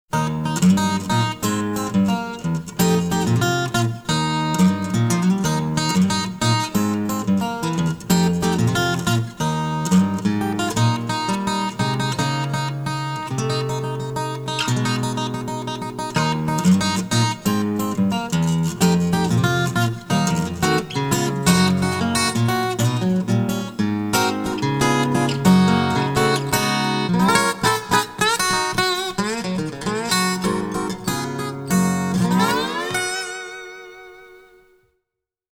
*-6-* Installing seven strings on an old acoustic guitar.
Finally after tuning the seventh string to a high G (workshop page) ,
Old MacDonald had a farm with a slide sounded better.
OldMacDonald had a farm(old guitar).MP3